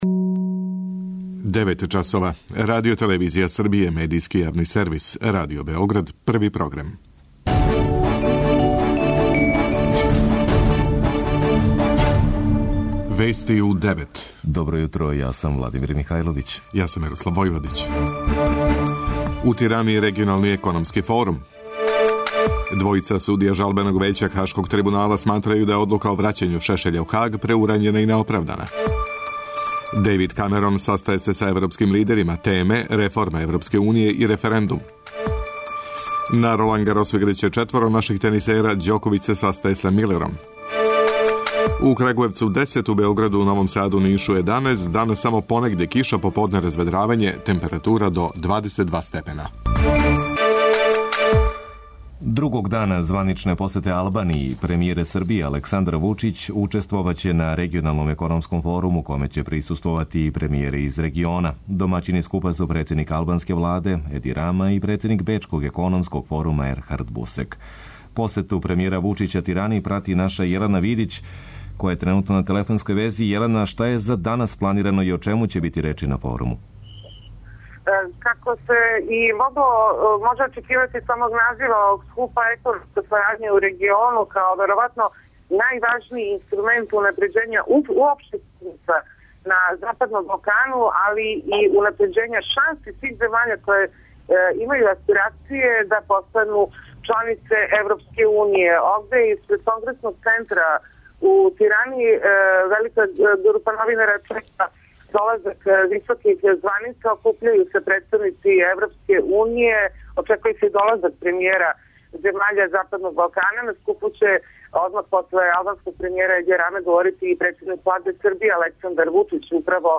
преузми : 10.81 MB Вести у 9 Autor: разни аутори Преглед најважнијиx информација из земље из света.